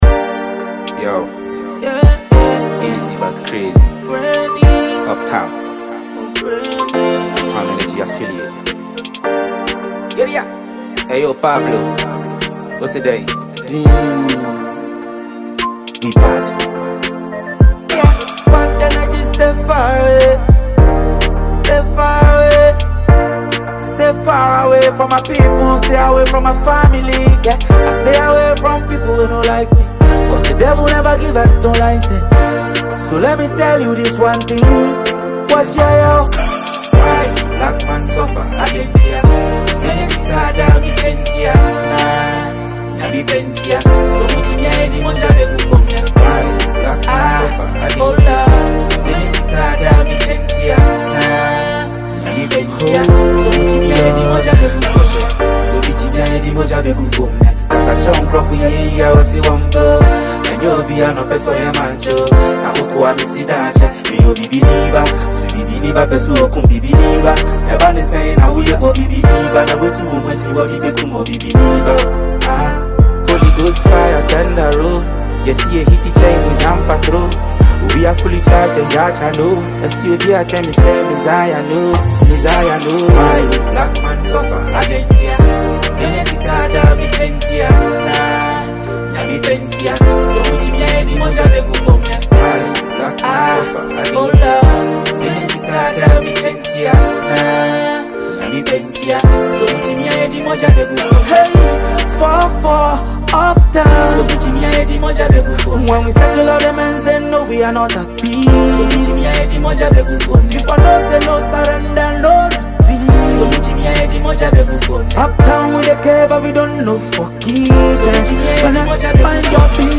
Enjoy this dope rap production.